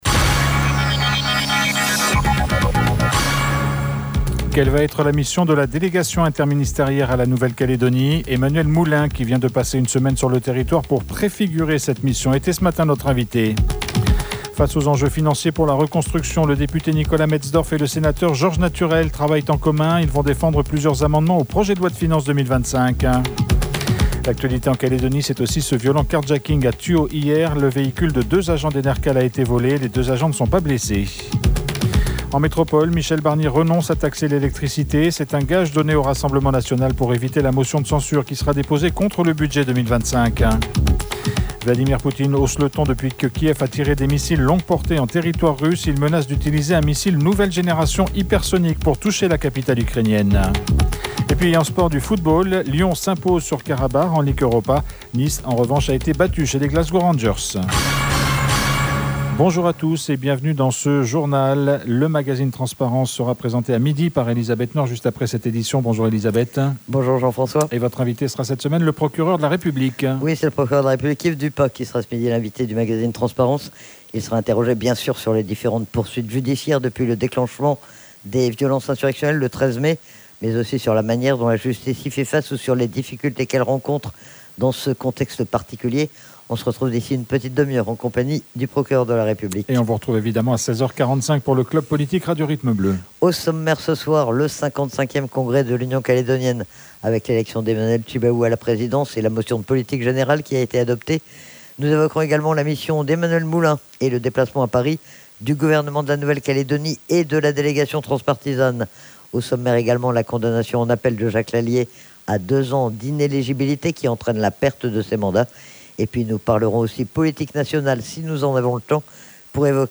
Il a dressé ce matin au micro de RRB n bilan de sa mission et il a aussi parlé de la suite.